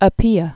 (ə-pēə, äpē)